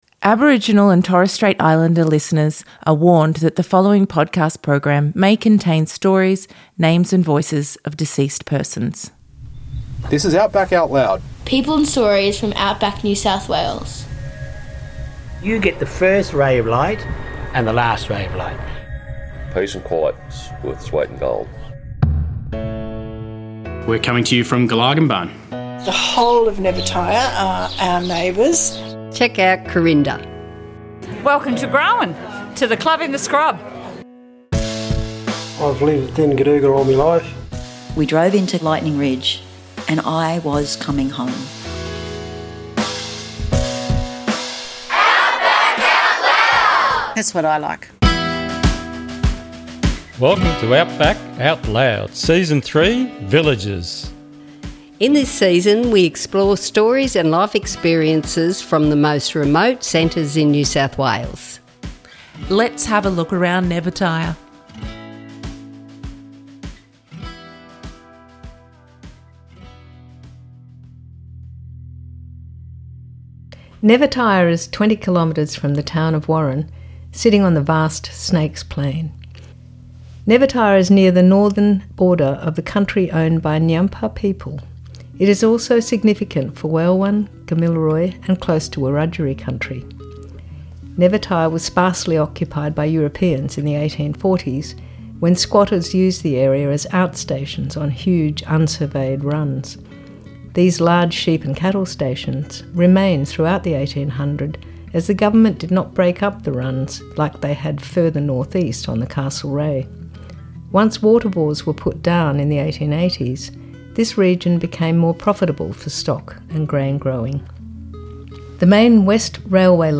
In this episode we hear interviews from people in Nevertire NSW 0n Ngiyampaa / Wangaaypuwan & Weilwan country.